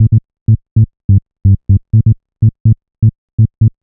cch_bass_romance_125_Am.wav